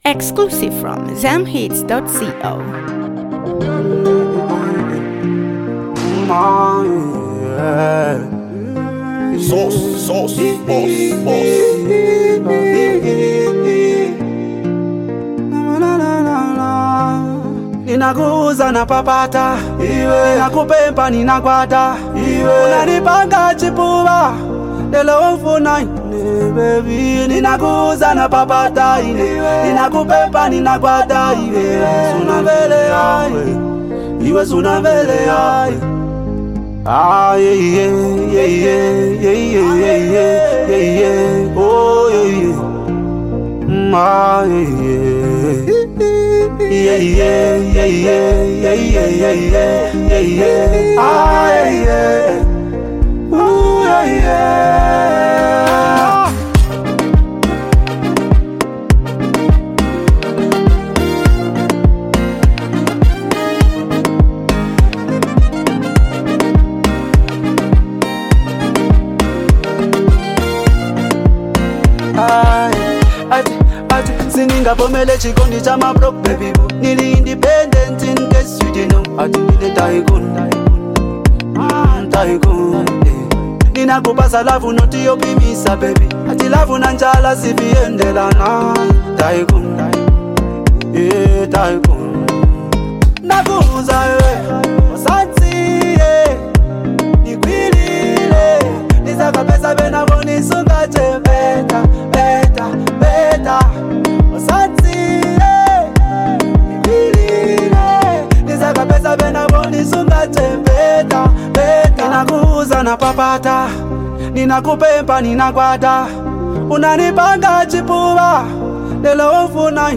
soulful voice
a perfect blend of Afrobeat and Zambian contemporary sound
The instrumental is smooth yet powerful
melodic delivery